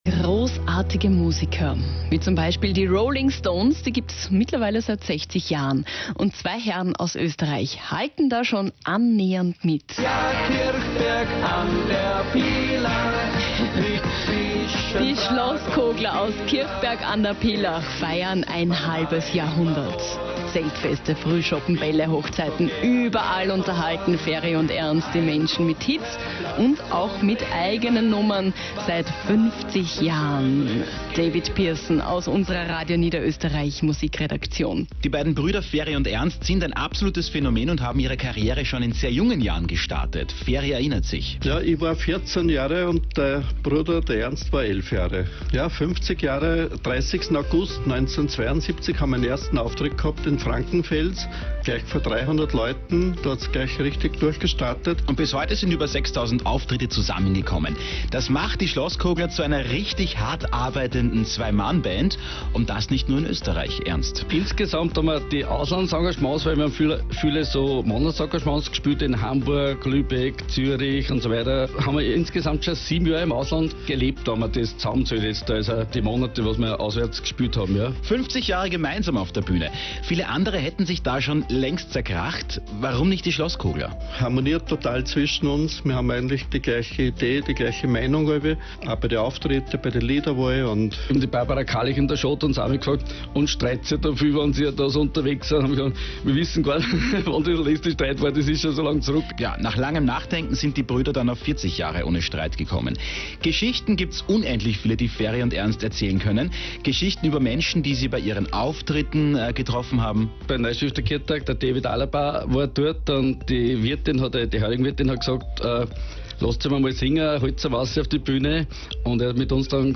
Anlässlich des 50 Jahr Jubiläums wurden die Schlosskogler von ORF Niederösterreich zu einem Radiointerview eingeladen.